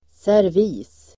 Uttal: [serv'i:s]